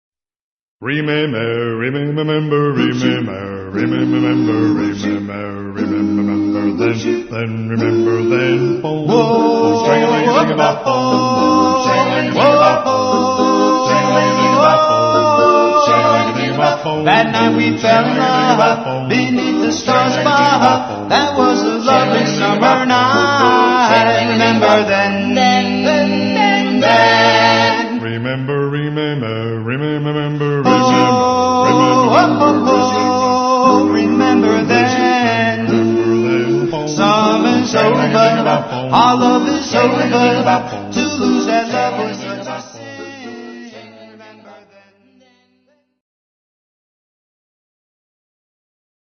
authentic four-part harmonies